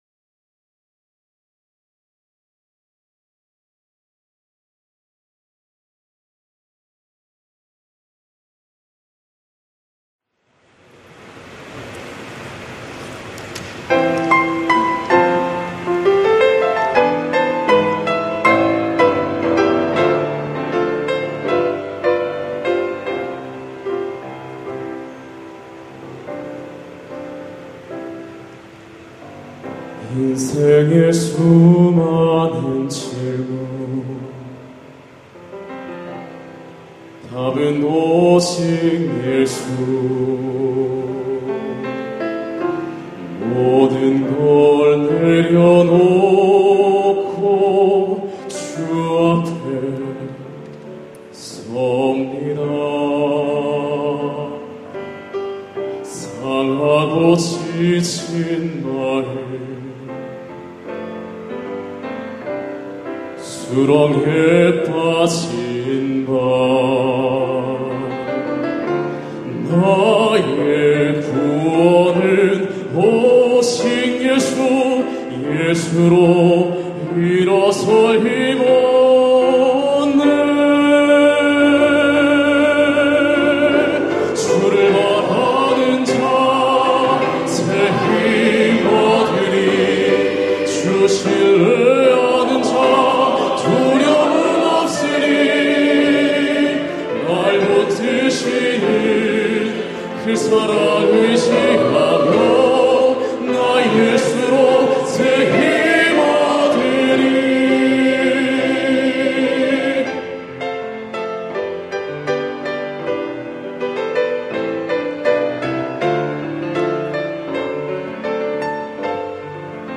예수로 새 힘 얻으리 > 찬양영상